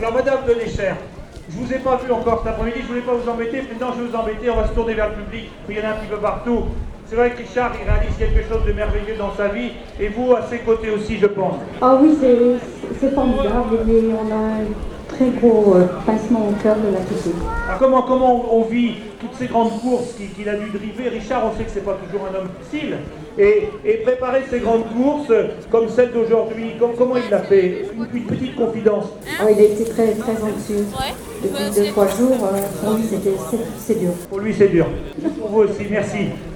Les liens Audio, sont les enregistrement que j'ai éffectués le 31/12/2003 sur l'hippodrome du Bouscat à Bordeaux lors de la dernière course de FAN IDOLE.